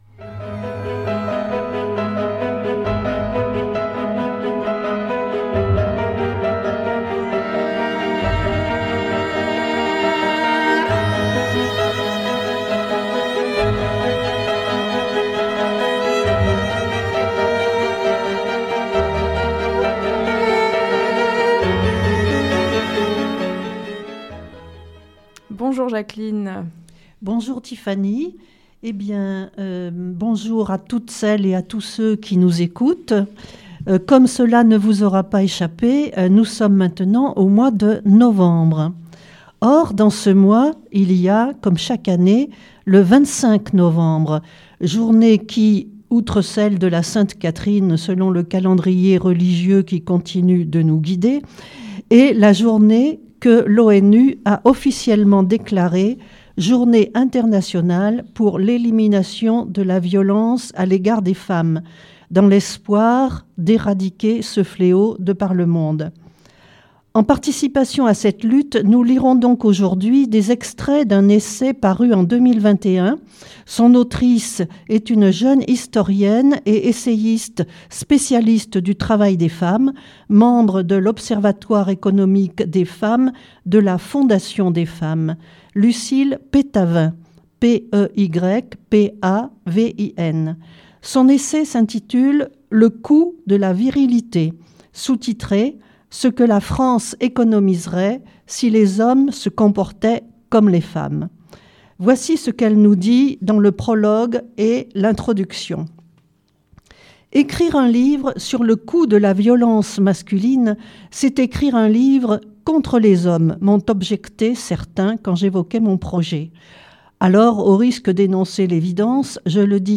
Lecture de textes de littérature féminine et féministe N°9